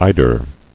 [ei·der]